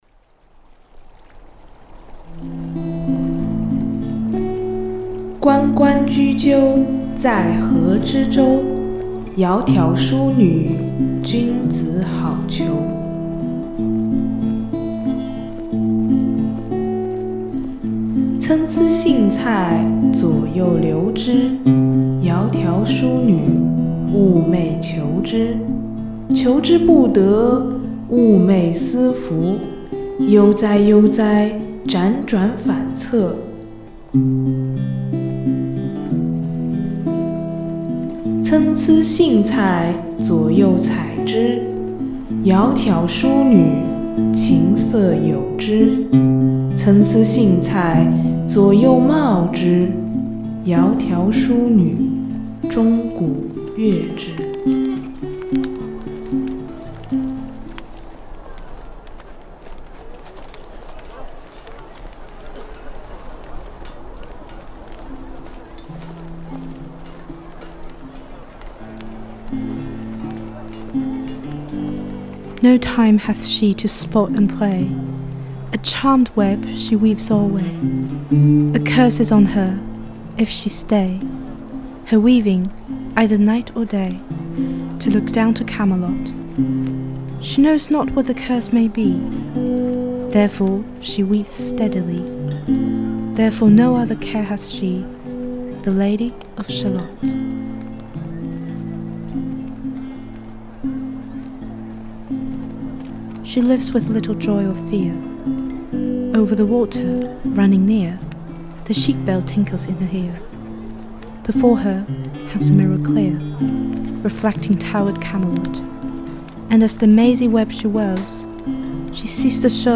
Reprise à la guitare